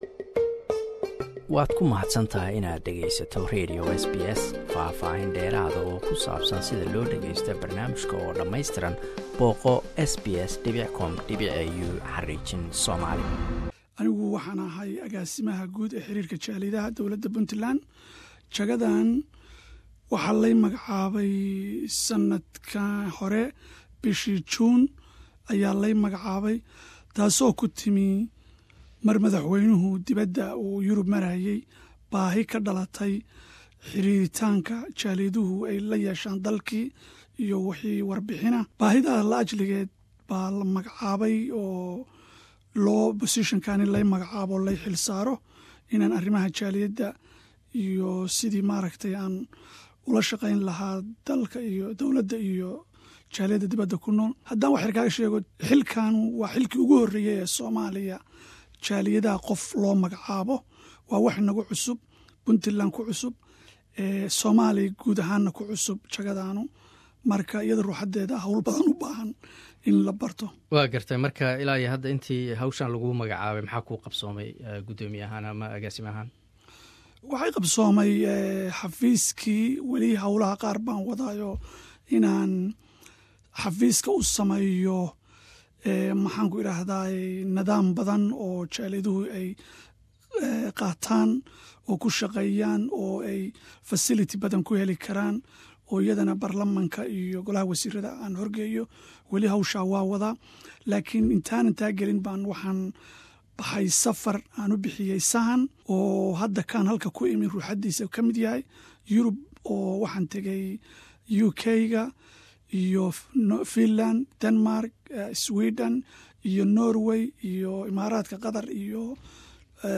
Waraysi: Agaasimaha qurbajoogta Puntland.